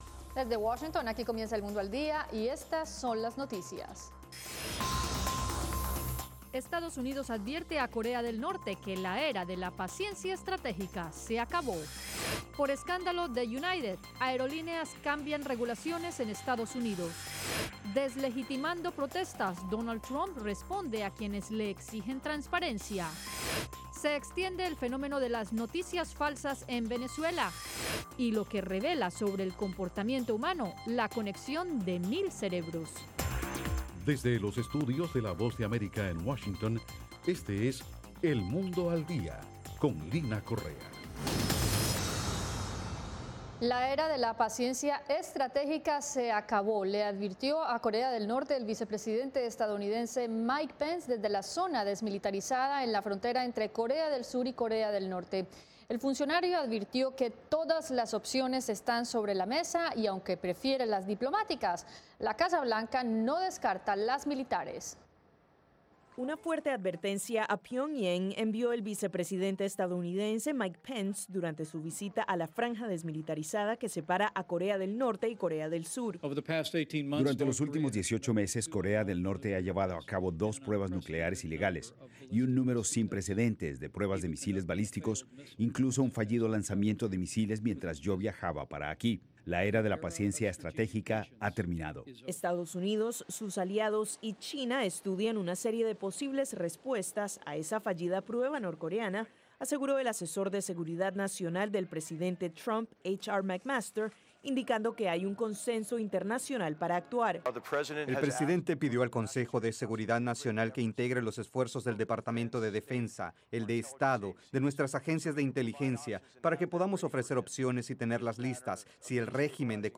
Las noticias del acontecer de Estados Unidos y el mundo